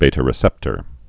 (bātə-rĭ-sĕptər, bē-)